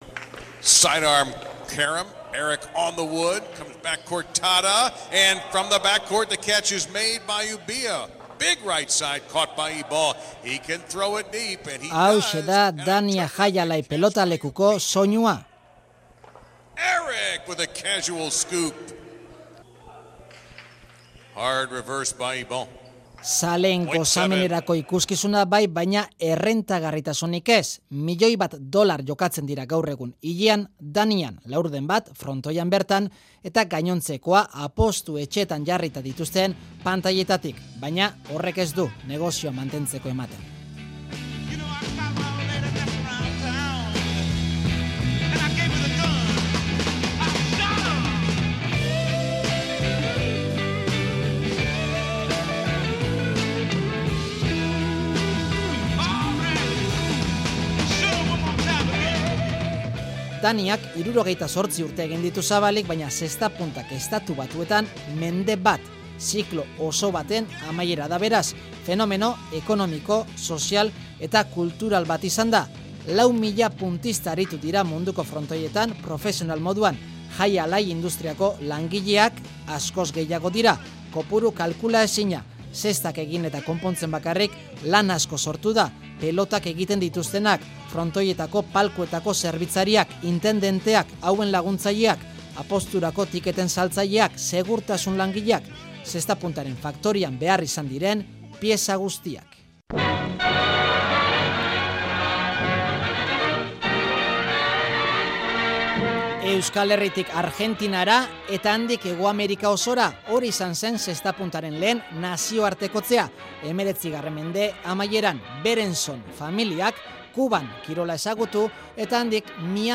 Audioa: Dani Jai Alai frontoia itxiera erreportajea